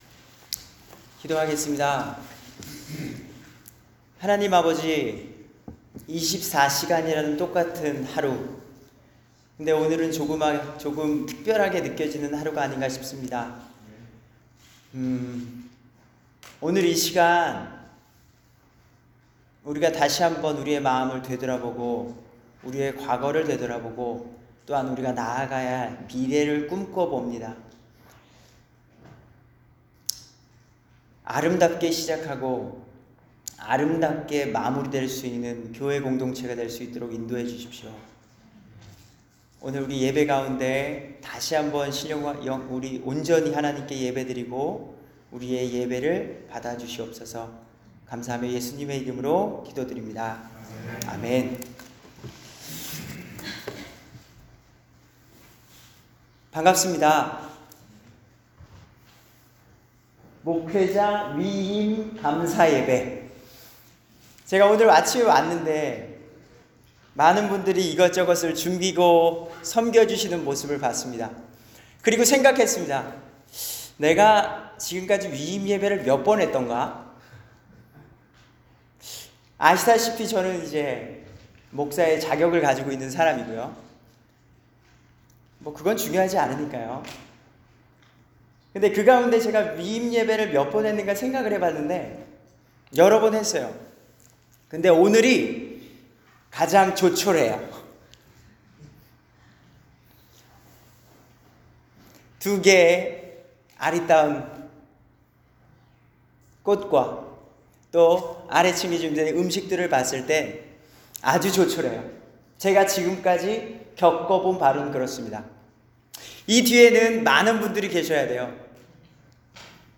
신실한 교회-위임예배 설교